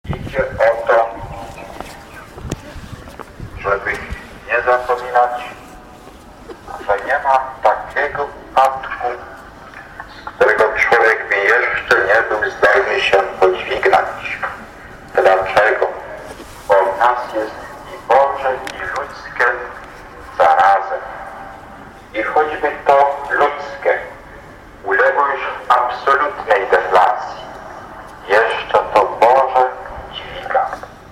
W czasie drogi krzyżowej można było usłyszeć archiwalne nagrania z oryginalnym głosem samego kard. Wyszyńskiego.
Prezentujemy fragmenty wypowiedzi kard. Stefana Wyszyńskiego: